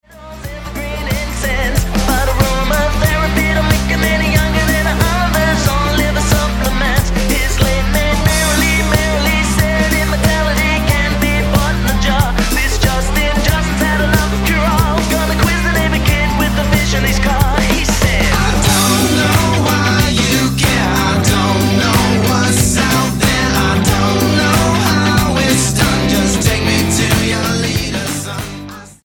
STYLE: Rock
Timelessly catchy pop-rock.